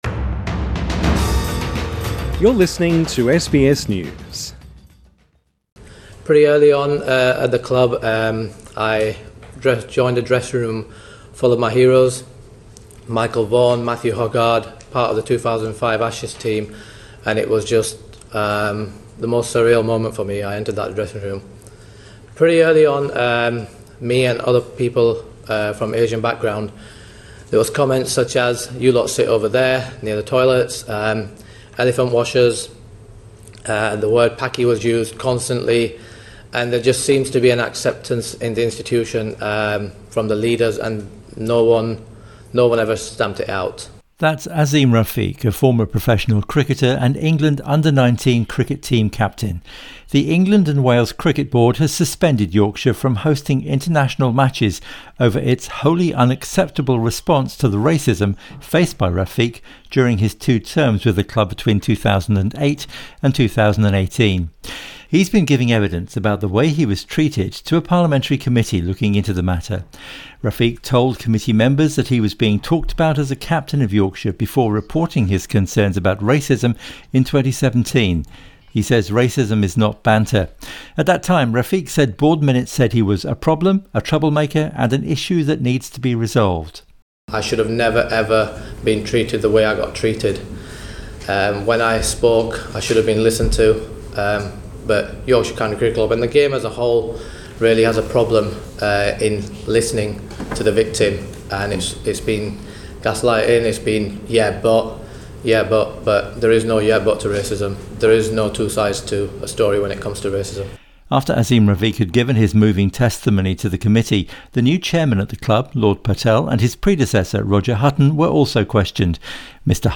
Cricketer Azeem Rafiq gives emotional testimony about racism
Former cricketer Azeem Rafiq gives evidence during a parliamentary hearing Source: AAP